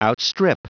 Prononciation du mot outstrip en anglais (fichier audio)